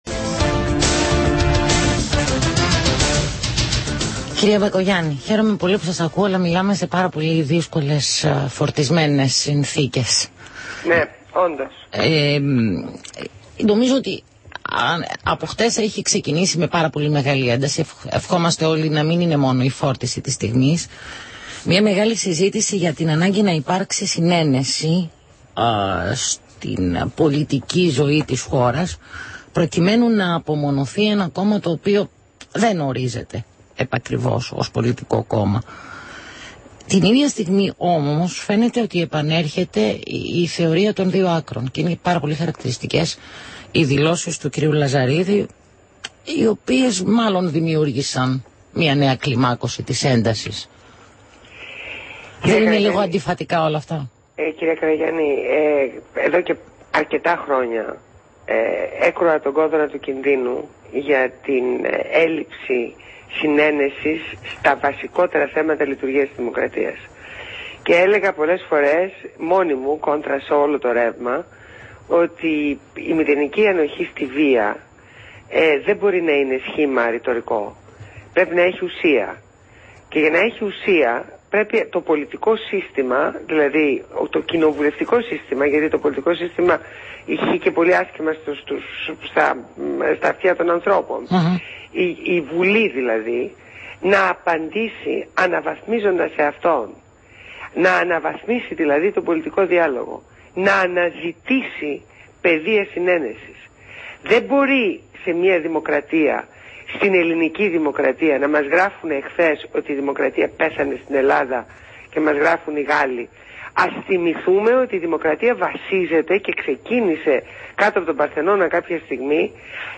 Συνέντευξη στο ραδιόφωνο Αθήνα 98.4